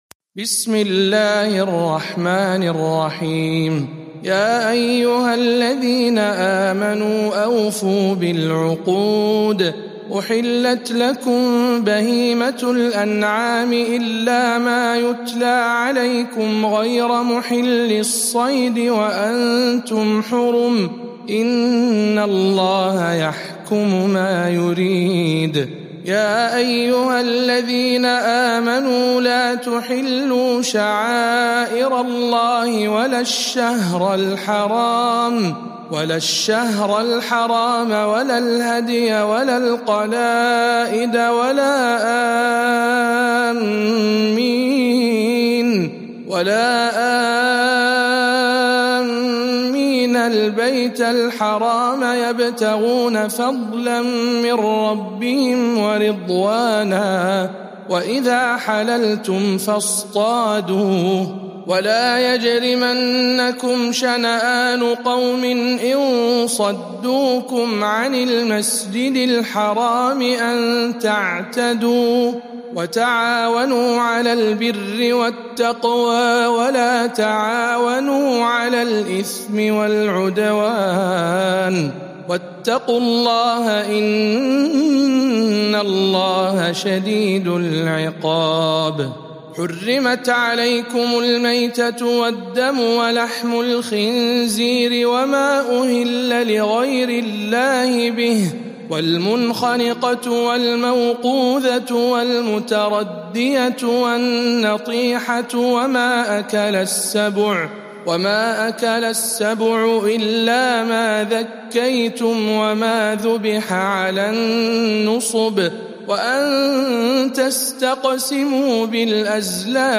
سورة المائدة برواية الدوري عن أبي عمرو